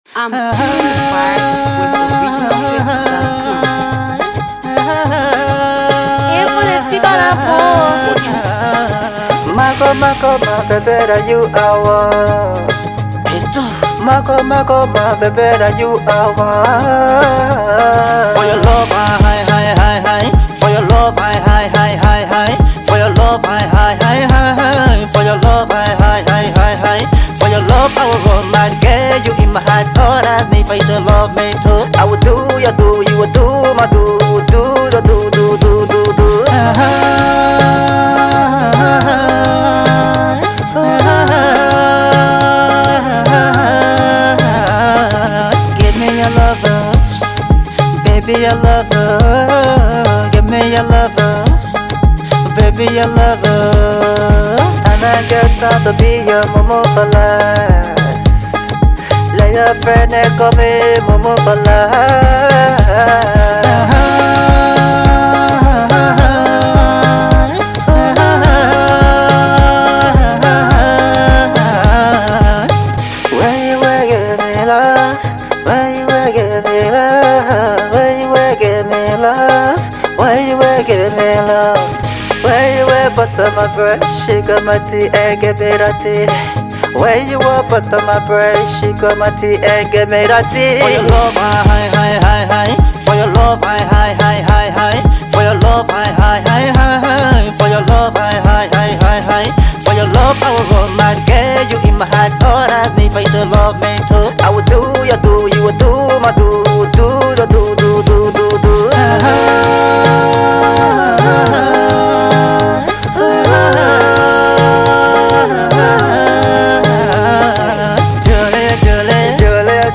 melody jam